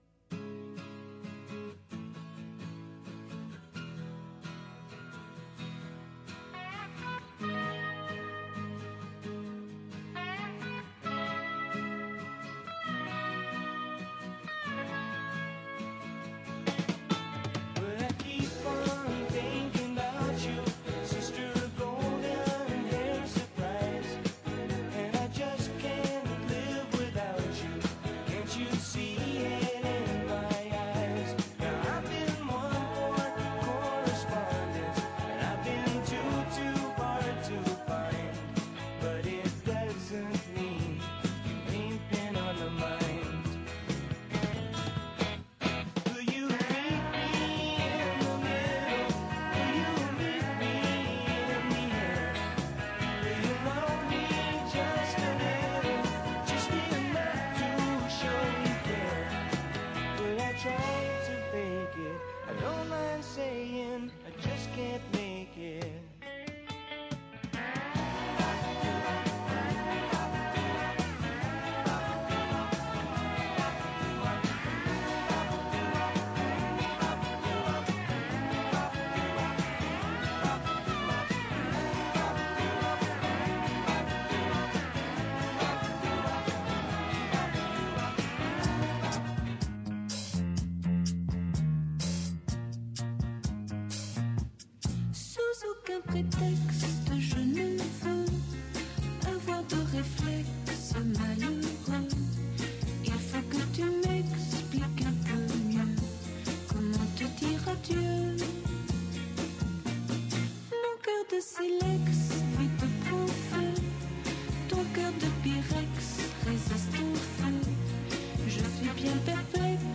Ce jeudi, dans la dernière émission de la saison « L’invité du 12/13h », nous avons reçu Julien Dubois, maire de Dax, à l’approche des Fêtes de Dax, qui auront lieu du 13 au 17 août.